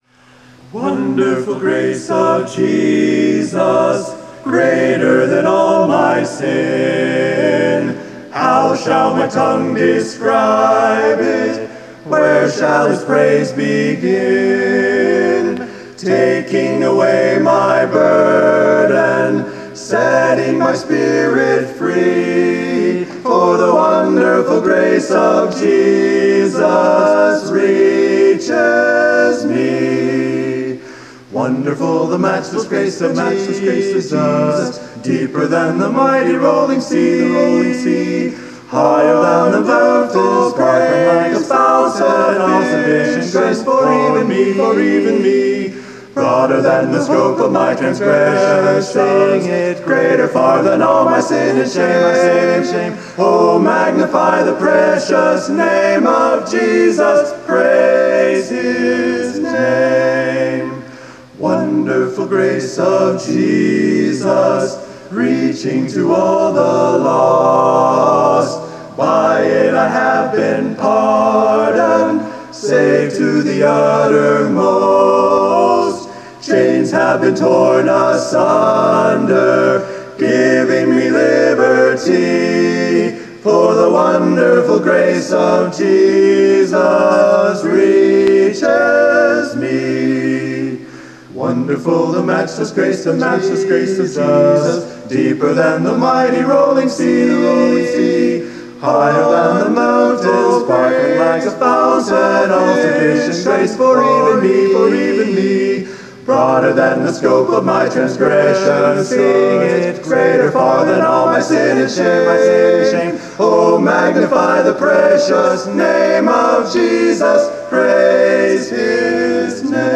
They only rehearsed four or five times, but they still sounded pretty good.
Here are recordings (of okay quality) of the songs they sang: